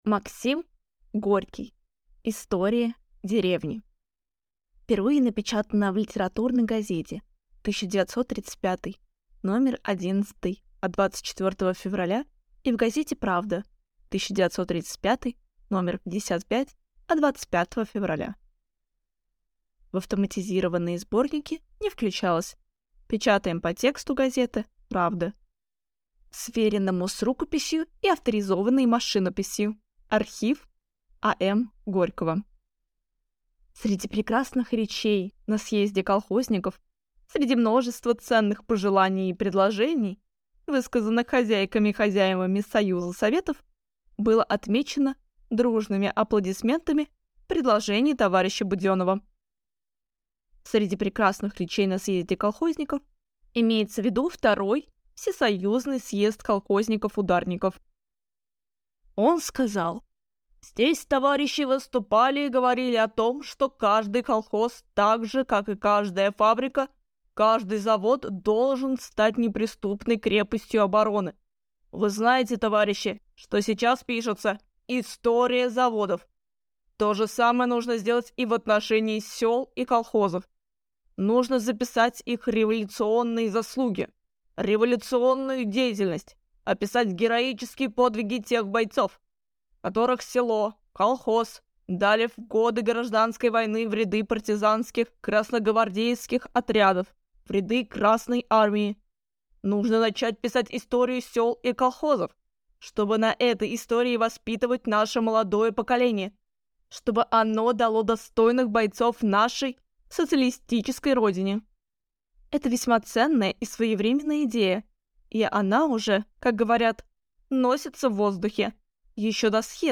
Аудиокнига «История деревни» | Библиотека аудиокниг